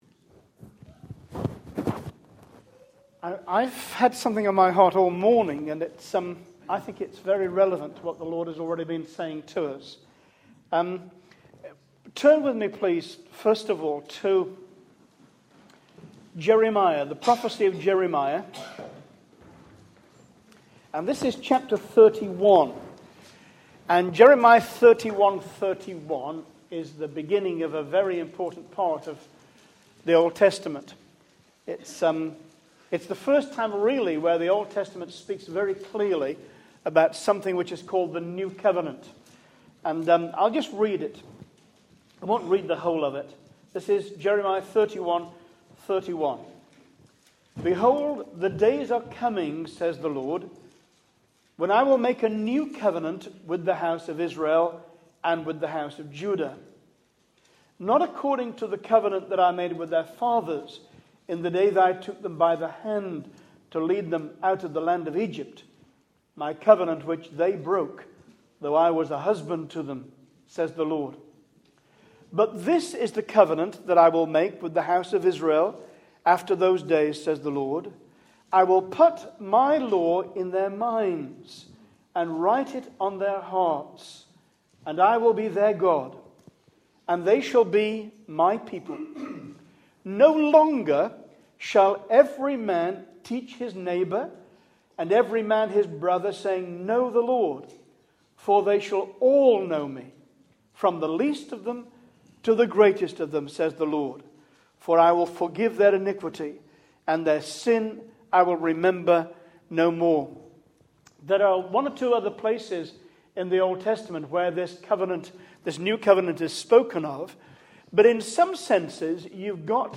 Message: “Biblical Discipleship”
Becoming a disciple of Jesus Christ. Shared in the Earley Christian Fellowship morning meeting c2012?